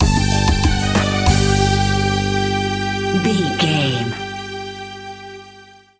Aeolian/Minor
percussion